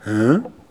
Les sons ont été découpés en morceaux exploitables. 2017-04-10 17:58:57 +02:00 122 KiB Raw Permalink History
hein-etonnement_01.wav